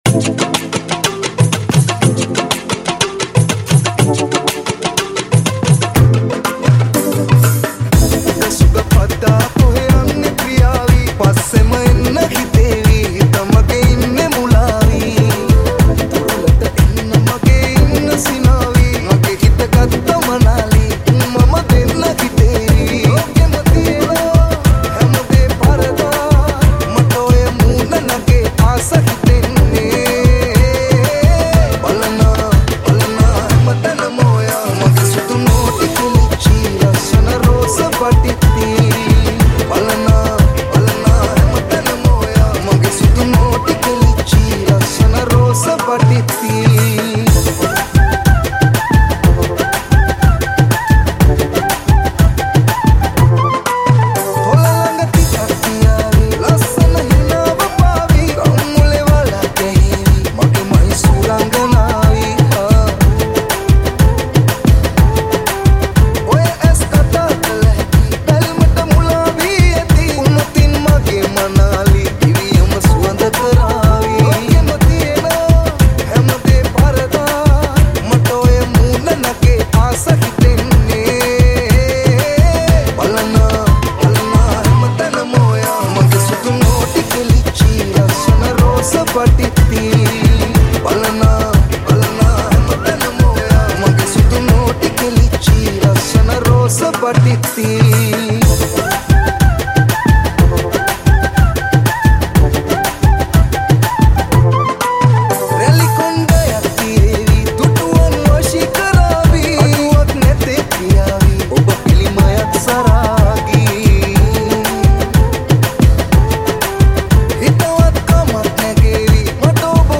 High quality Sri Lankan remix MP3 (2.8).